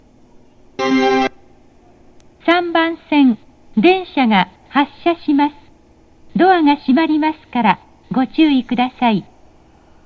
発車メロディー
●スピーカー：National天井丸型
即切り(3番線)